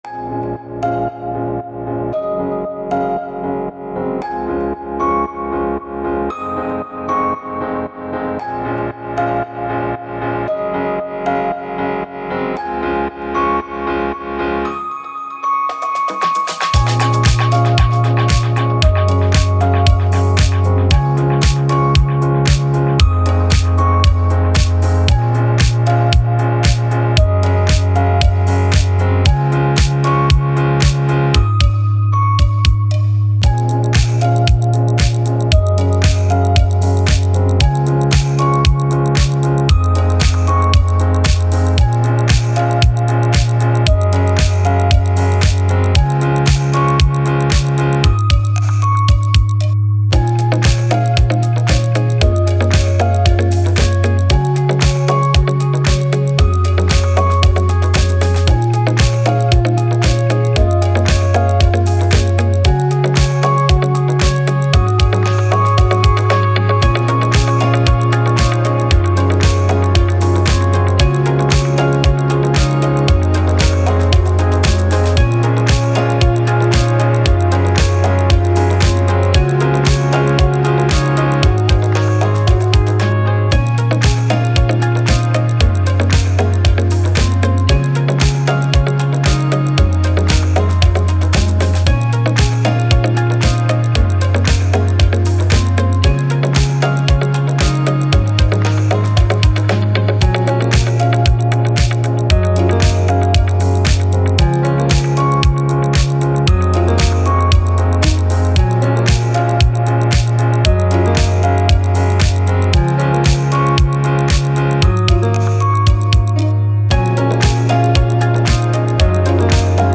Dance, Electronic, Upbeat, Positive